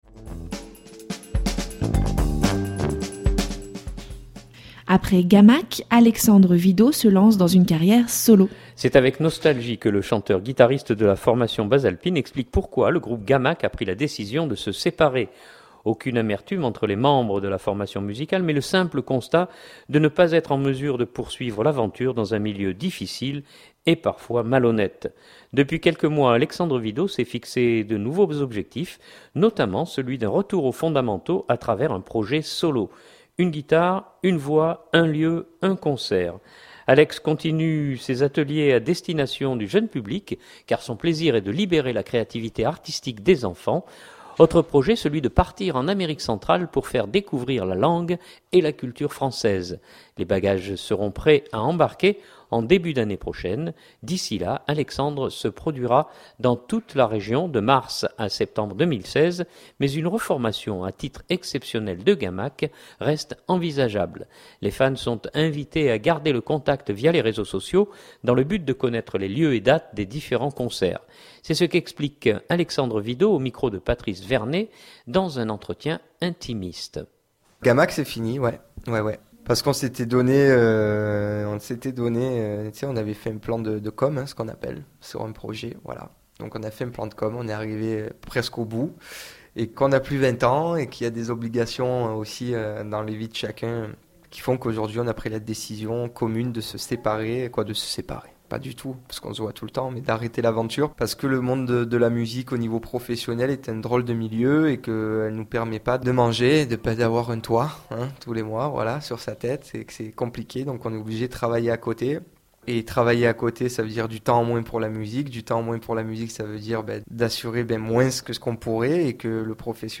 un entretien intimiste